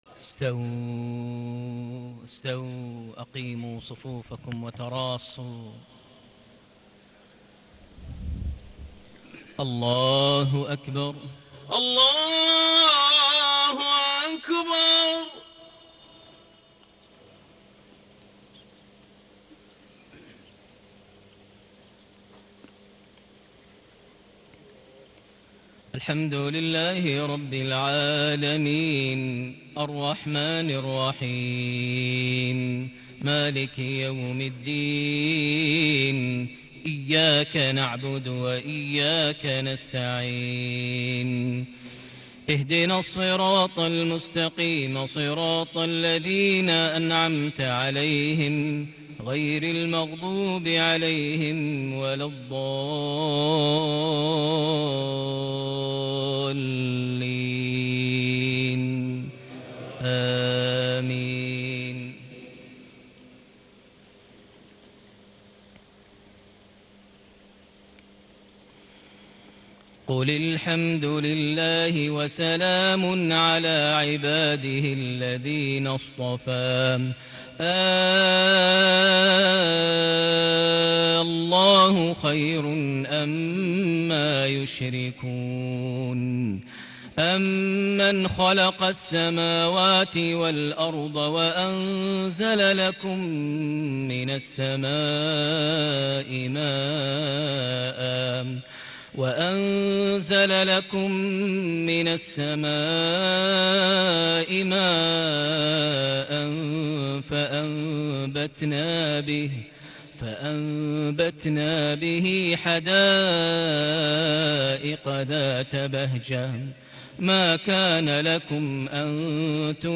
صلاة المغرب ١٢ جماد الآخر ١٤٣٨هـ سورة النمل ٥٩-٦٦ > 1438 هـ > الفروض - تلاوات ماهر المعيقلي